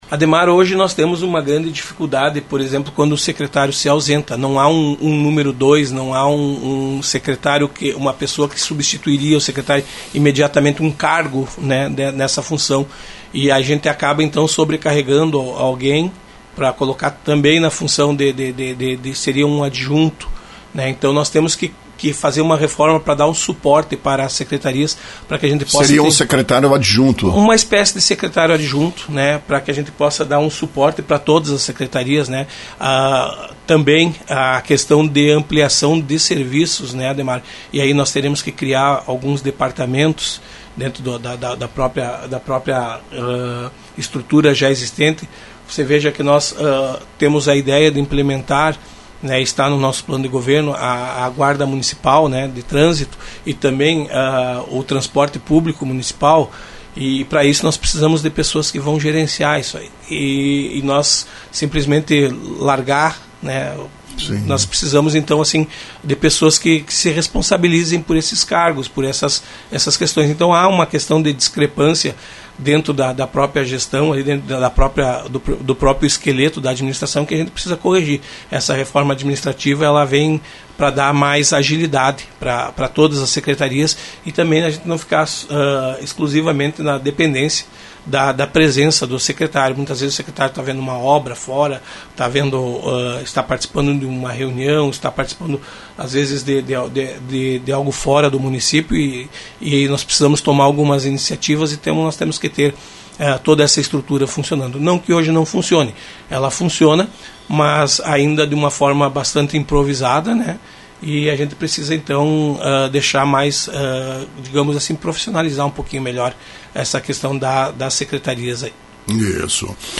Por ocasião de sua posse, prefeito Eloir Morona fez referência, em seu pronunciamento, de que pretende implantar uma reforma administrativa na administração municipal de Lagoa Vermelha. Na manhã desta segunda-feira foi questionado, em entrevista à Rádio Lagoa FM, sobre como seria a reforma administrativa na prática.